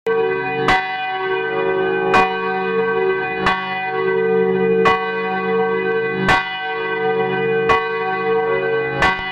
Church Bell Ringing